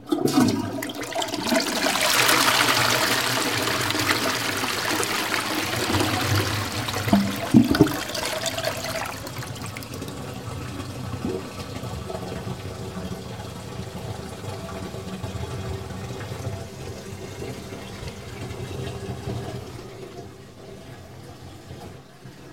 Soloshow (DERZEIT-IRRE-KUNST, DER-KUNST-IHRE-FREIZEIT) at the KnollGalerieWien (A)
„Artificial neighbour“ (see image N°2) uses the sound of a toilet flush. Four flushing bell syphons are installed inside of canisters positioned one under another. The closed water circulation system is operated using a small pump. By that you get the impression you constantly hear your neighbors using their toilets or taking a shower.
syphon.mp3